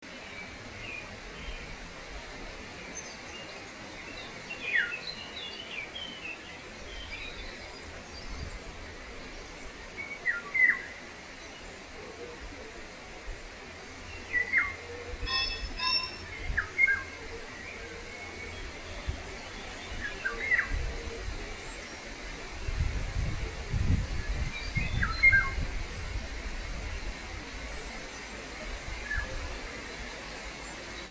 There was some excitement during May 2008 when one of our members identified the call of a Golden Oriole among the Poplar trees bordering School Common.
Although the bird was unseen and therefore not photographed, an audio recording was made.
For a second opinion just ask the local pheasant who manages to get in the act about half way through.
oriel.mp3